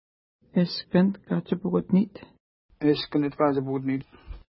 ID: 53 Longitude: -59.2638 Latitude: 52.8014 Pronunciation: eʃkənt ka:tʃipukutni:t Translation: Where Hanging Antlers Block the Way Feature: lake Explanation: Caribou antlers were hung on a tree along the way.